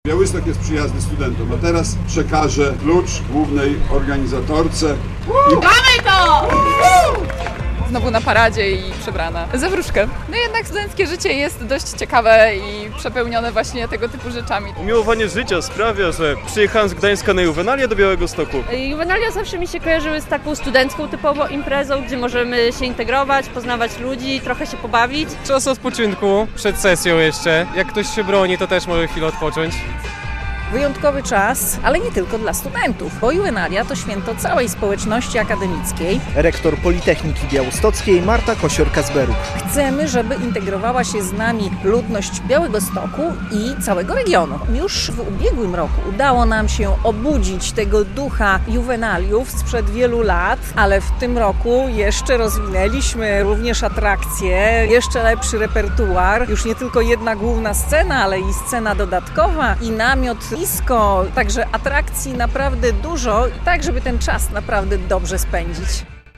Juwenalia Białystok - ulicami miasta przeszła parada studentów - relacja